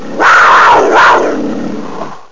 Amiga 8-bit Sampled Voice
giaguaro1.mp3